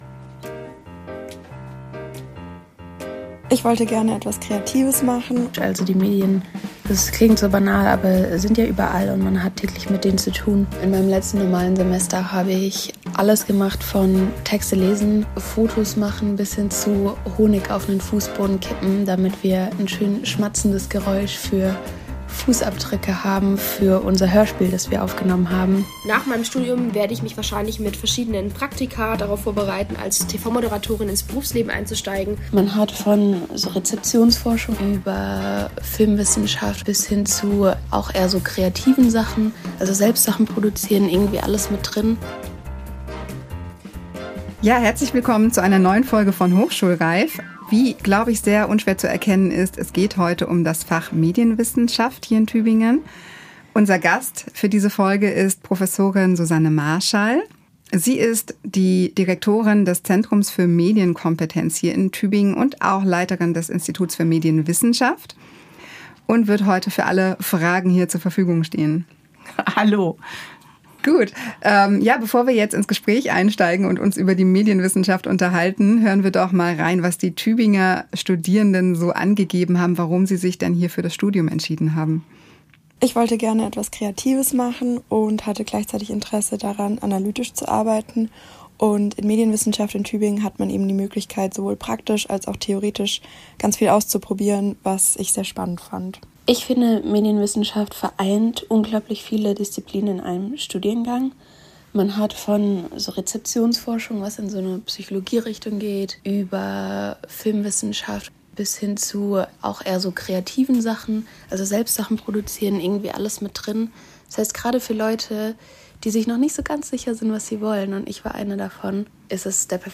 Zudem haben wir Studierende gefragt, was sie am Studium begeistert, wie eine typische Studienwoche bei ihnen aussieht und in welchen Berufen sie später arbeiten möchten.